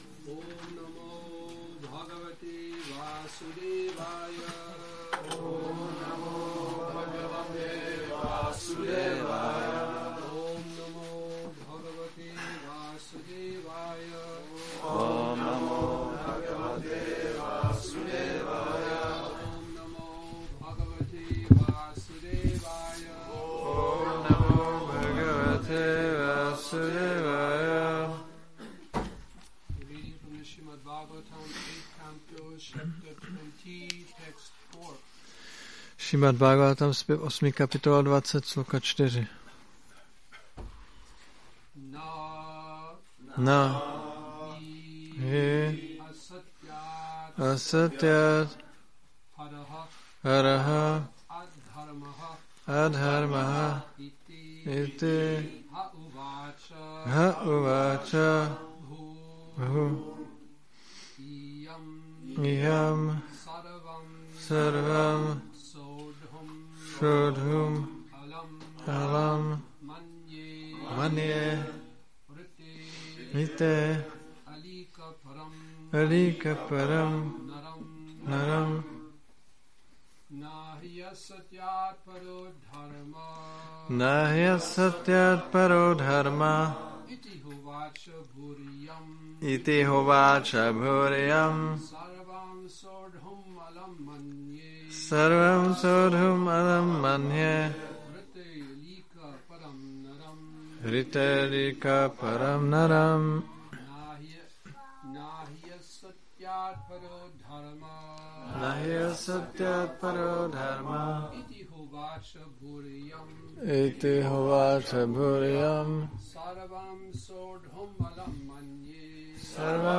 Šrí Šrí Nitái Navadvípačandra mandir
Přednáška SB-8.20.4